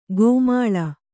♪ gōmāḷa